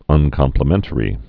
(ŭnkŏm-plə-mĕntə-rē, -mĕntrē)